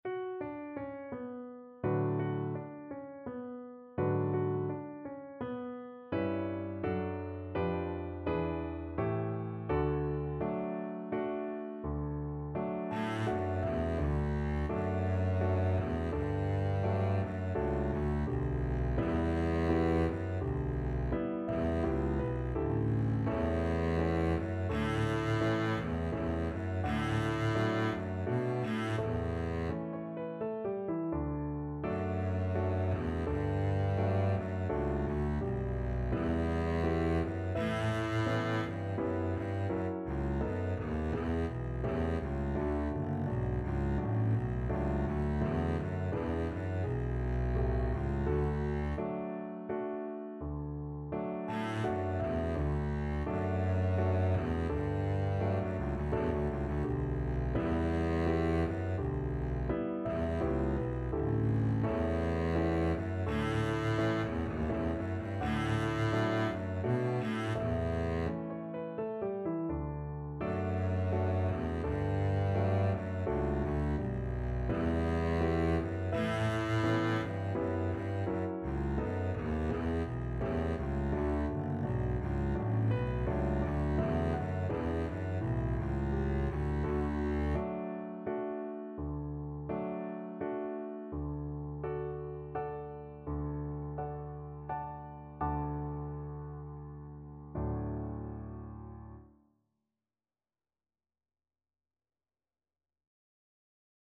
3/4 (View more 3/4 Music)
= 84 Andante non troppe e molto maestoso
F#2-B3